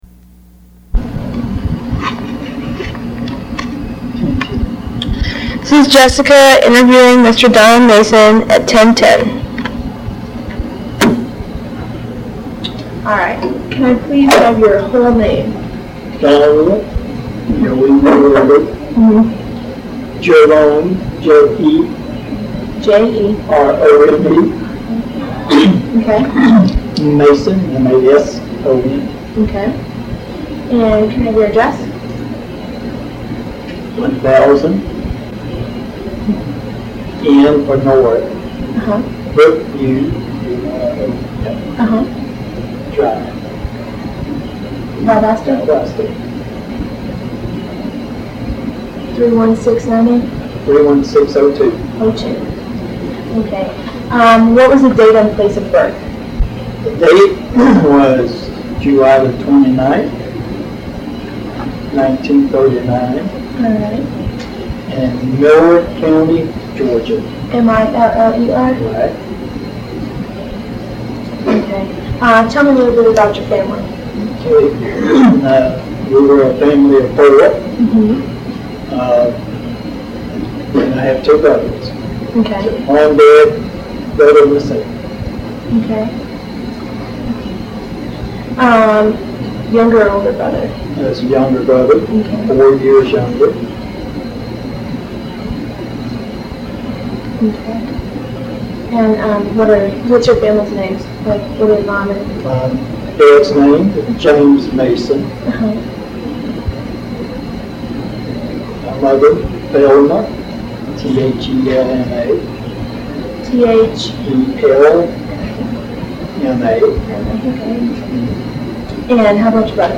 Audio file digitized from cassette tape. Part of the South Georgia Folklife Project at Valdosta State University Archives and Special Collections. Topics include Country and Bluegrass music.